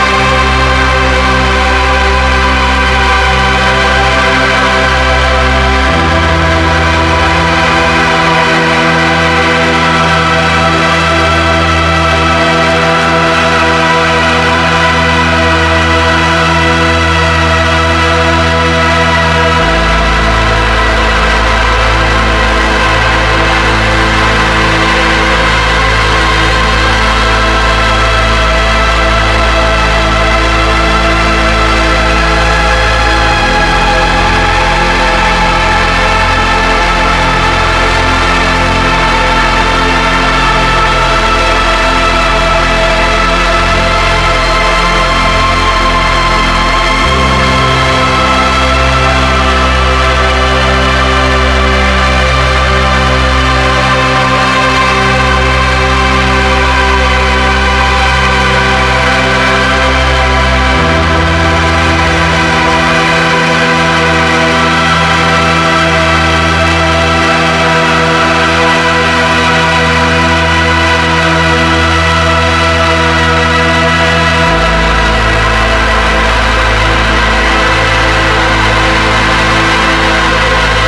Ambient Electronica / Synth Soundscape